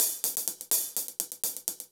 Index of /musicradar/ultimate-hihat-samples/125bpm
UHH_AcoustiHatA_125-04.wav